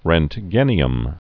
(rĕnt-gĕnē-əm, -jĕn- rŭnt-, rœnt-gen-)